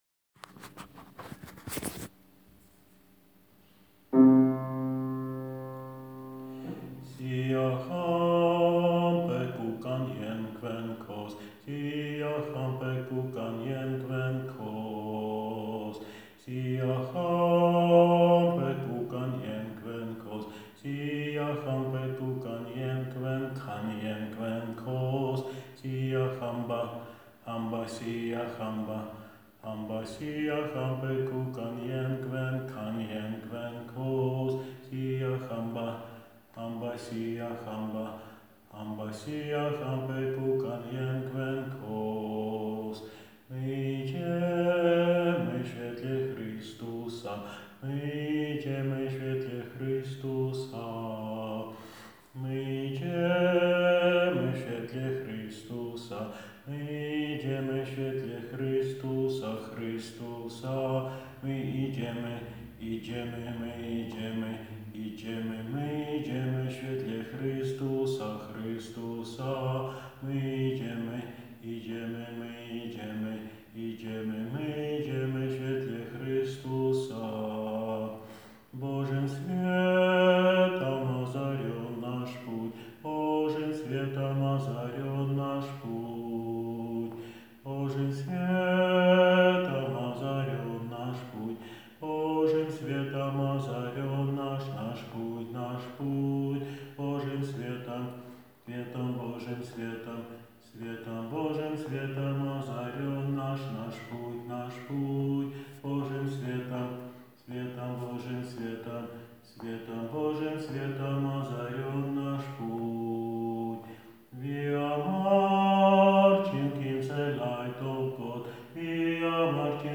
Siyahamba Alt - nagranie utworu z głosem nauczyciela ze słowami a capella (bez pomocy instrumentu)